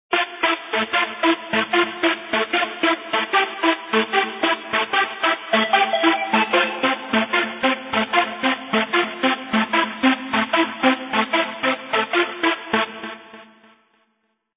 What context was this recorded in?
Very Very Important ID Needed! (recreation)